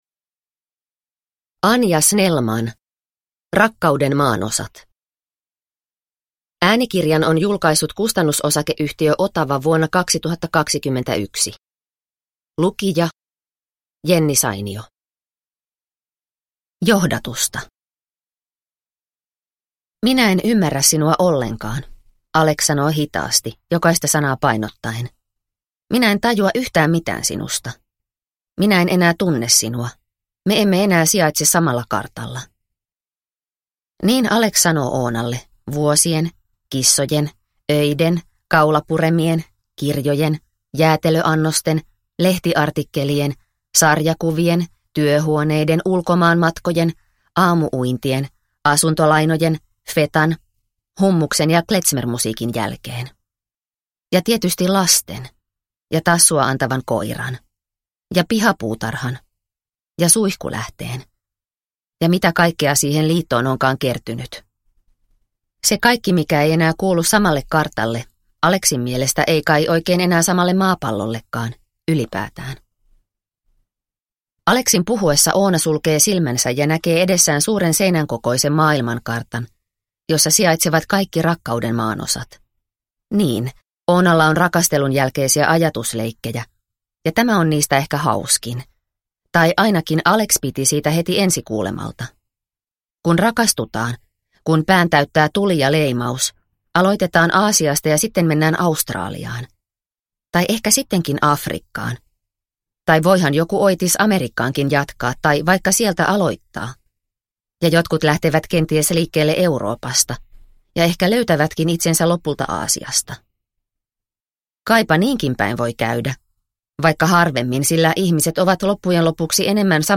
Rakkauden maanosat – Ljudbok – Laddas ner